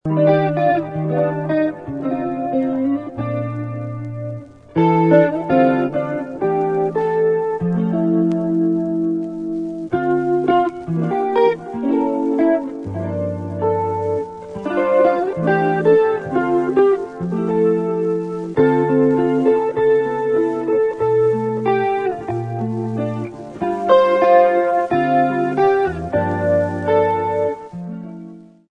シンセやパーカッション、ギターなどがとろけるように絡み合う、正にバレアリックな最高のアルバム！